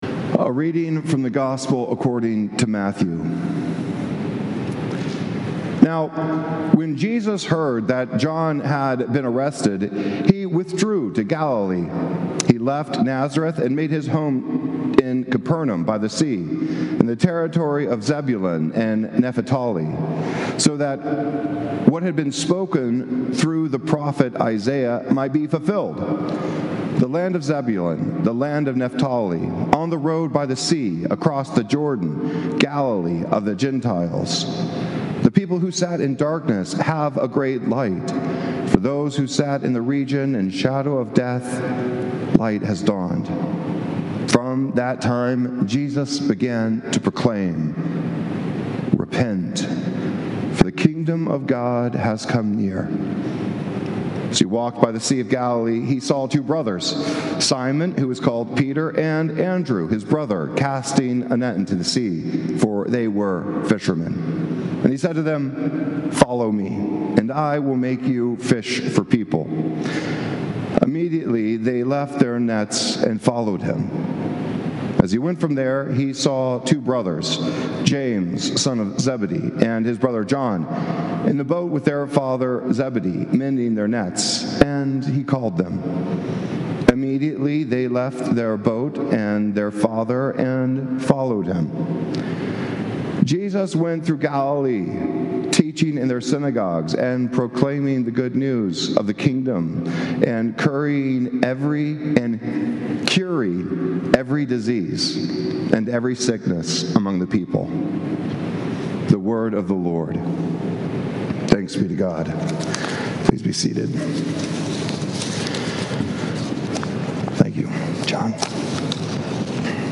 Sermons from St. Columba's in Washington, D.C. Sunday Sermon